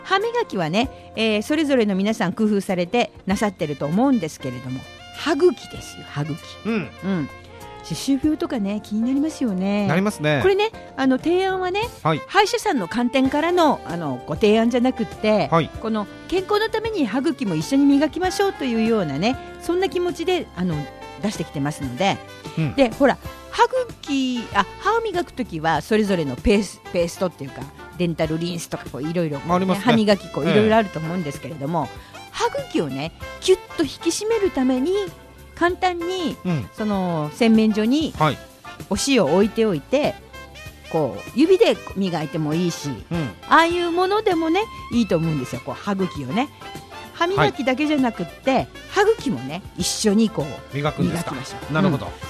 Natural Speed
*This is a conversation between two personalities.